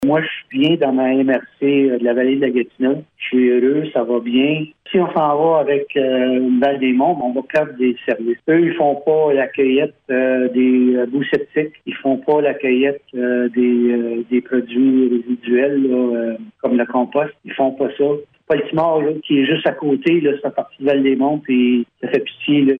Le nouveau maire, Pierre Nelson Renaud, n’a aucune hésitation lorsqu’il affirme qu’il n’est plus du tout question d’une fusion avec Val-des-Monts :